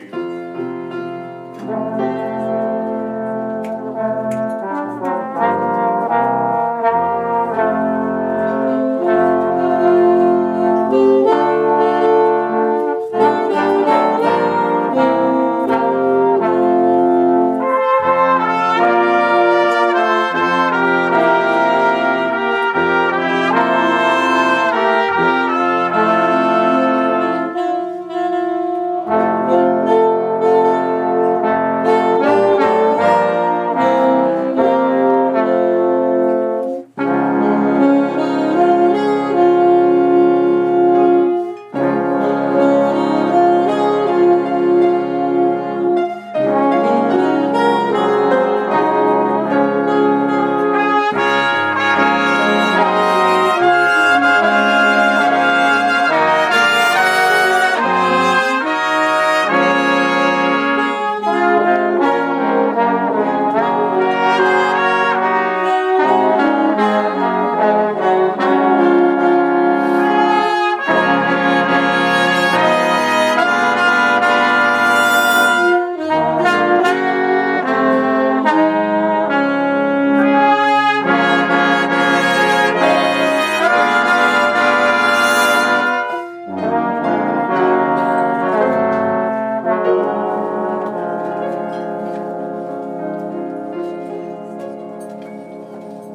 Bohemian Caverns band